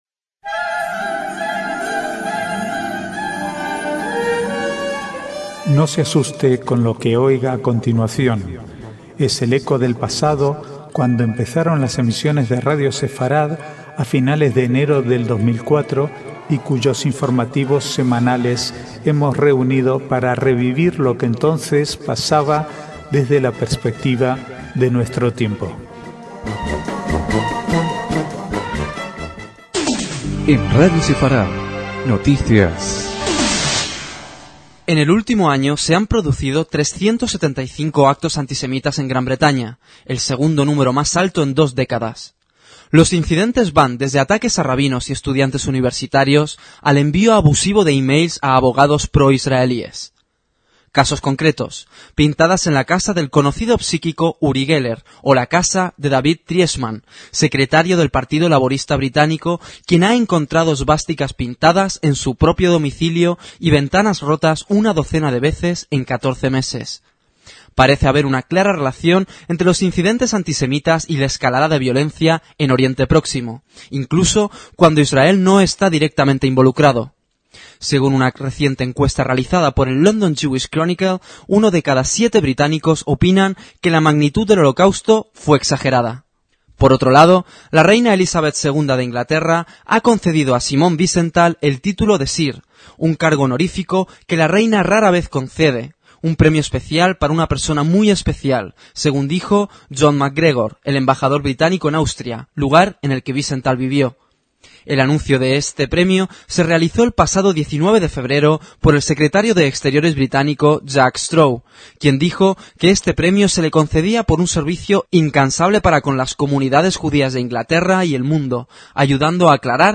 Archivo de noticias: del 24/2 al 5/3/2004